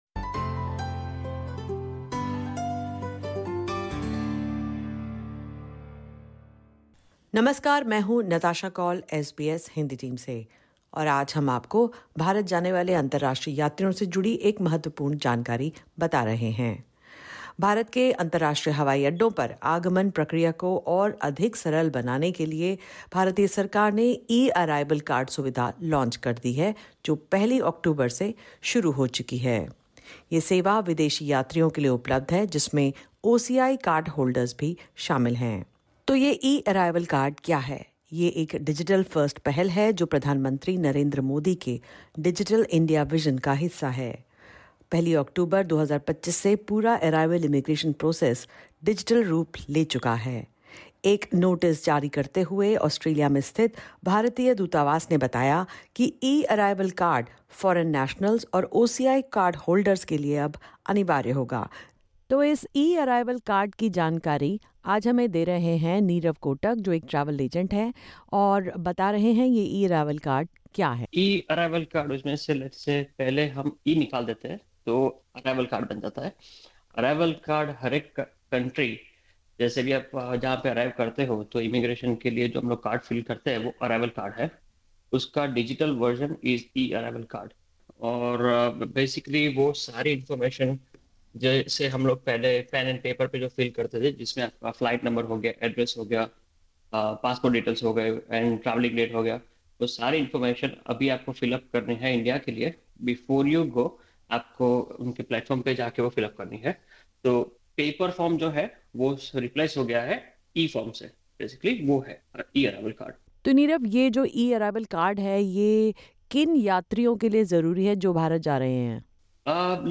From 1 October, all international travellers, including OCI cardholders, must submit an e-arrival card online within 72 hours before departure. The new digital system aims to streamline entry, reduce wait times, and eliminate manual data entry at airports. In this podcast, we talk about the new development with travel agent